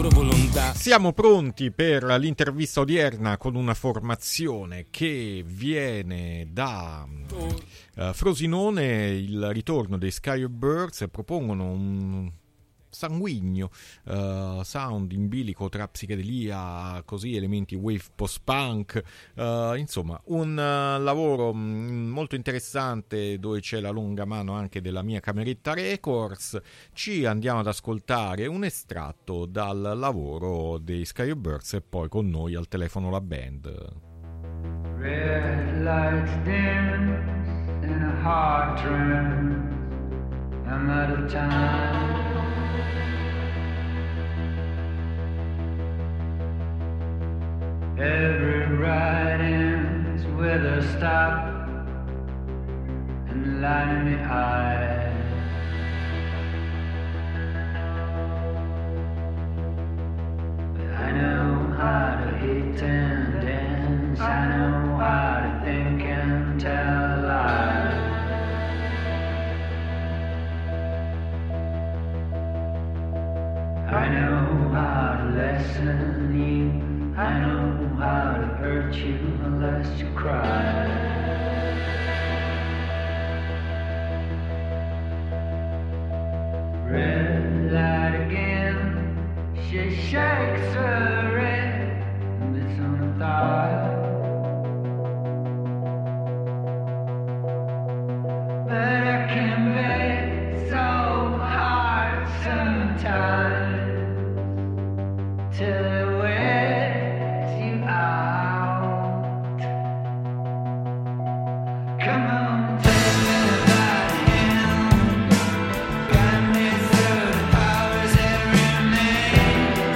Intervista agli Sky Of Birds - Alternitalia